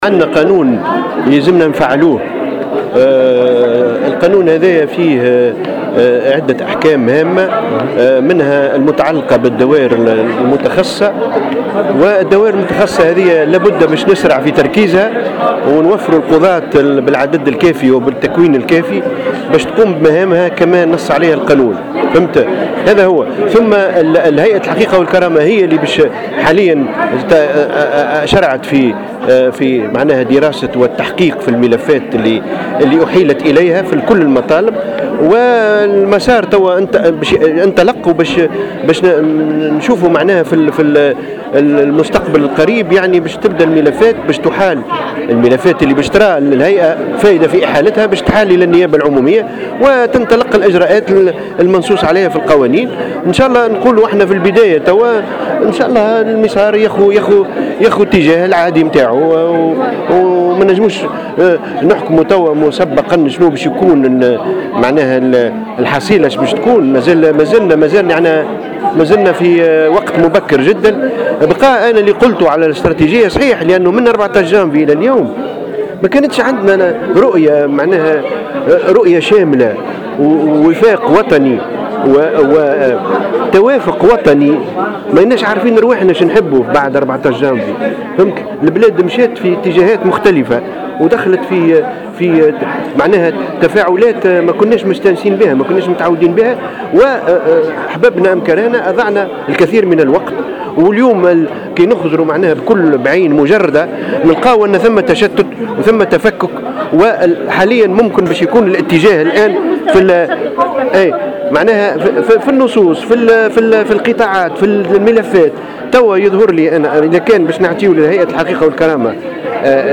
وأكد في تصريحات لـ"جوهرة أف أم"، على هامش ندوة حول مسار العدالة الانتقالية، أنه من غير الممكن الإعلان عن حصيلة هذه الملفات بصفة مسبقة ومبكرة، بحسب تعبيره.